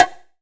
1ST-BONG1 -L.wav